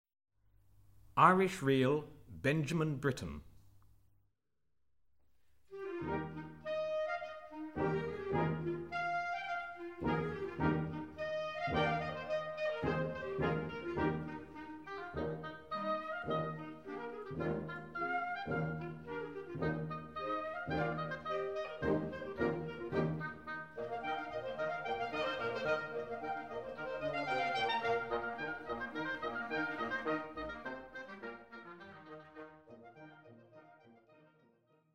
Voicing: Wind Band